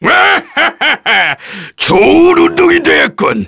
Ricardo-Vox_Victory_kr.wav